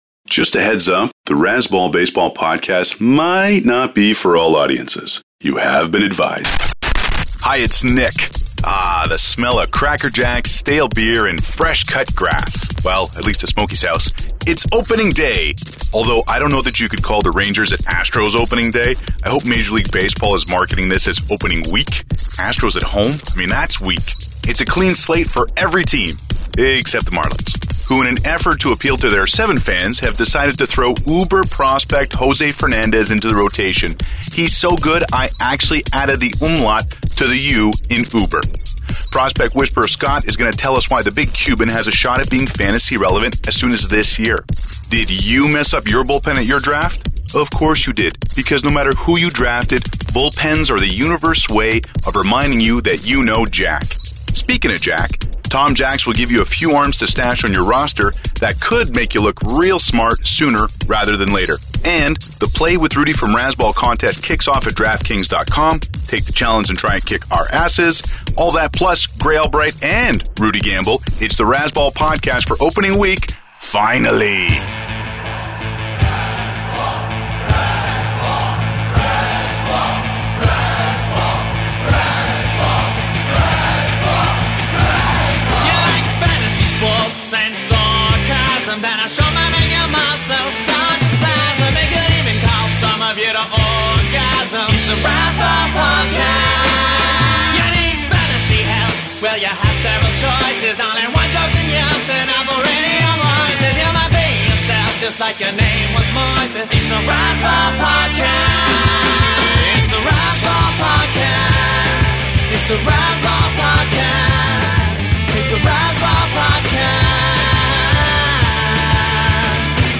I sing a song for the better part of the podcast. One song– Nay, one chorus, for 15 minutes.